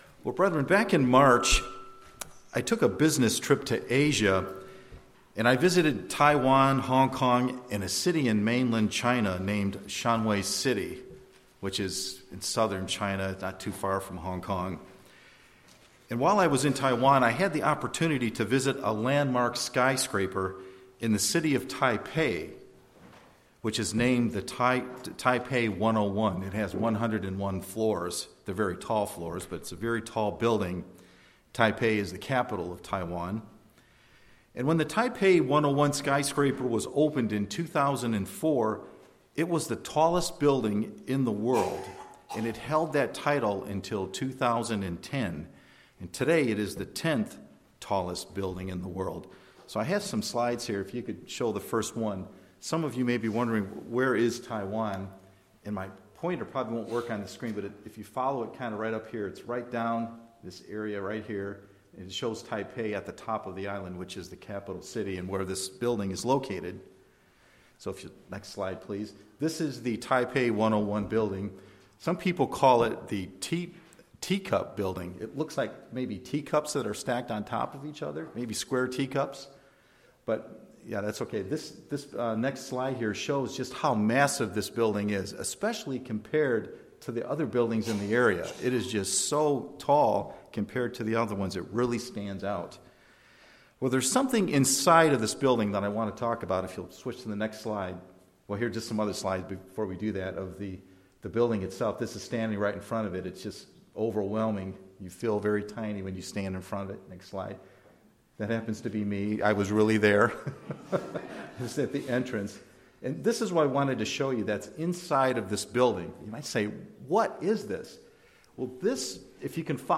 This sermon covers six points on how the Holy Spirit spiritually anchors us.